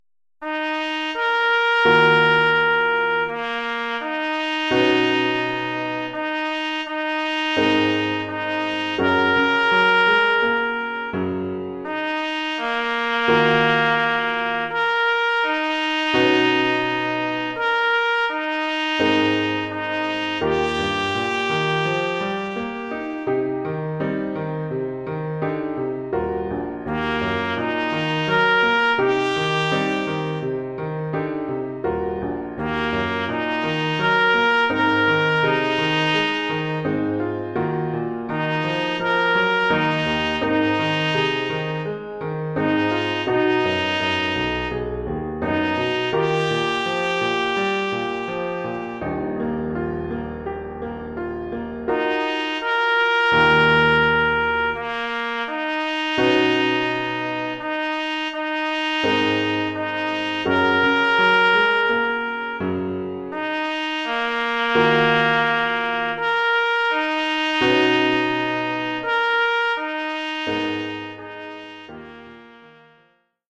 trompette basse mib et piano.